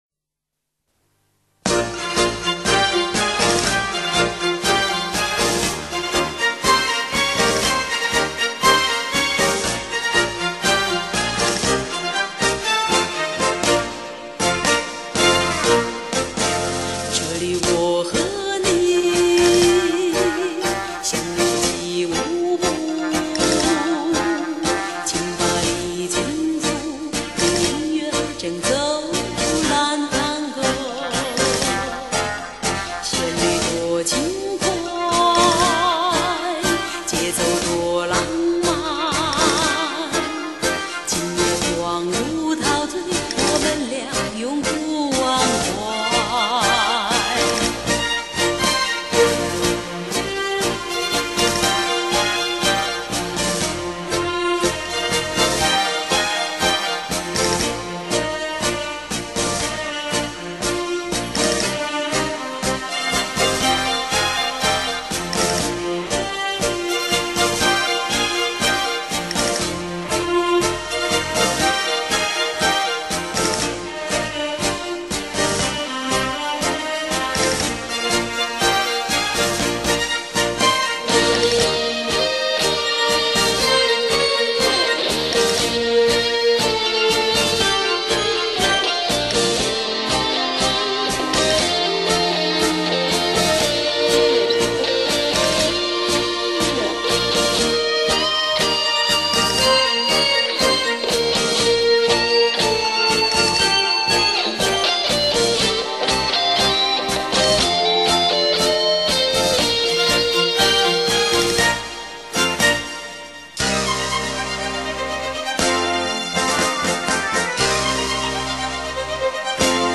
（探戈）